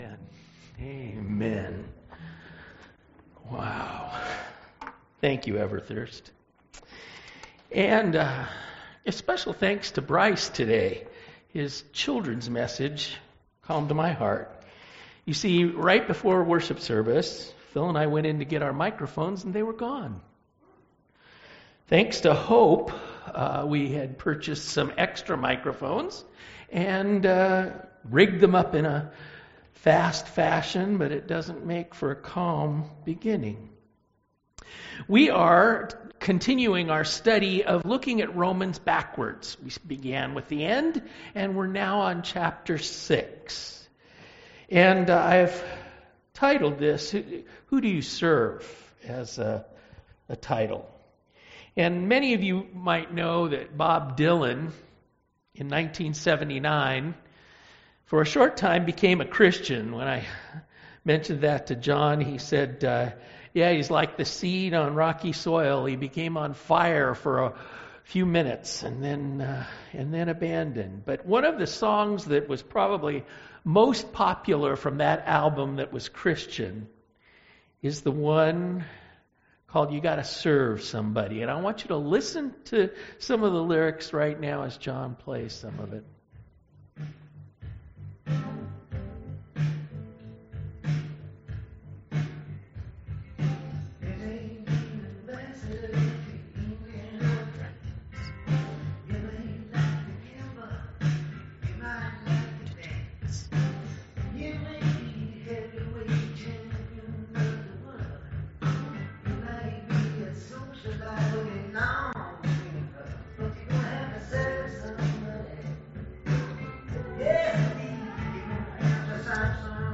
From Series: "!Sunday Morning - 9:00"